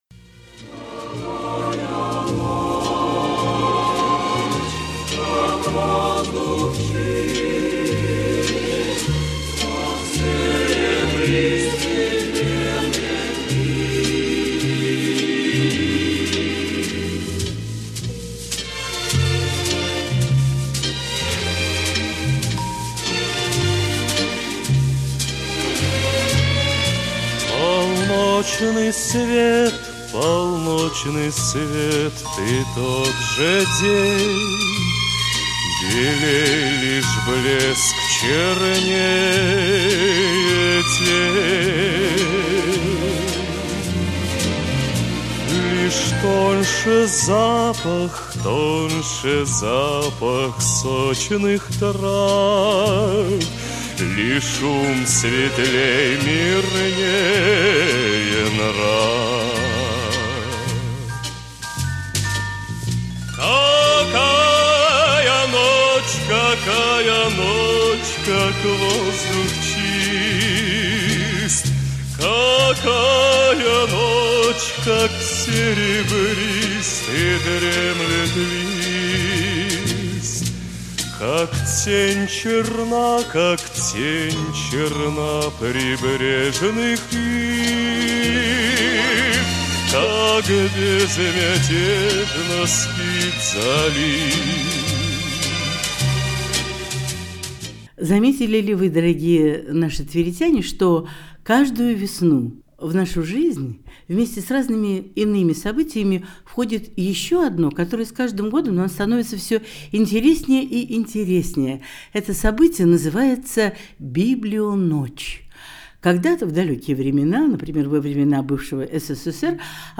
«Библионочь-2025» интервью с сотрудниками Центральной городской библиотеки им. А.И. Герцена - Муниципальная библиотечная система города Твери